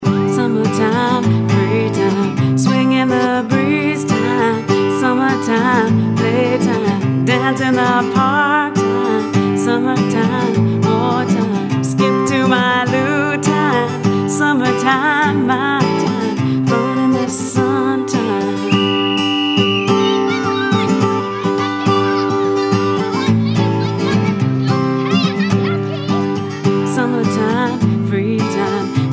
fun and quirky songs